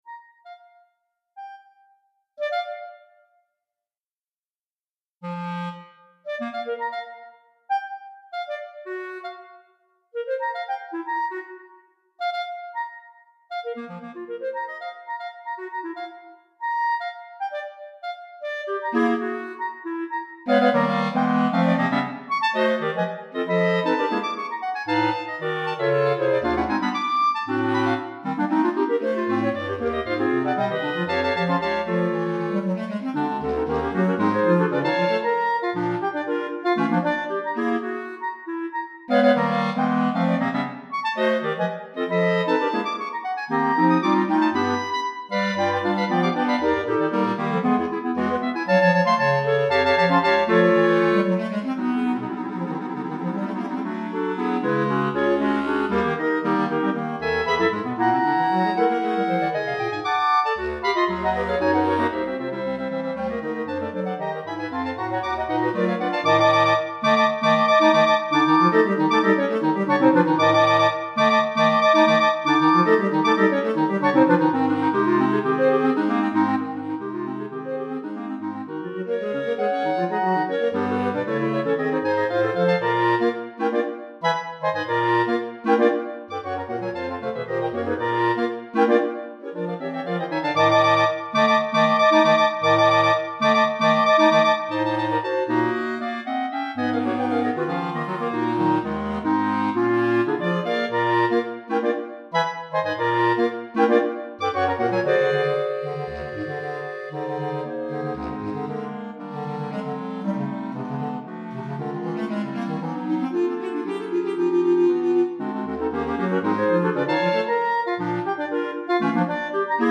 5 Clarinettes